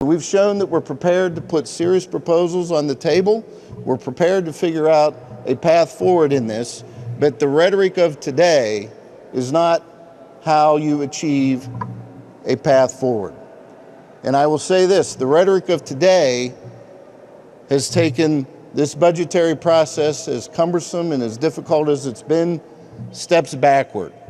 Senate Majority Leader Joe Pittman said the new spending plan was not progress.